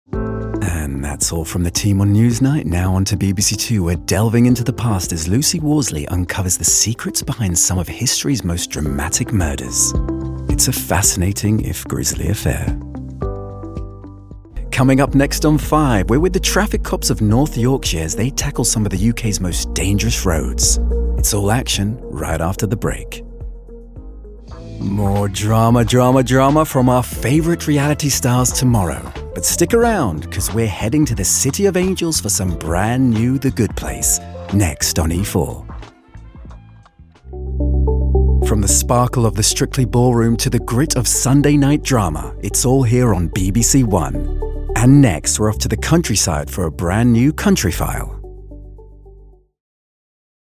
Continuity Showreel
Male
Neutral British
Cool
continuity-showreel.mp3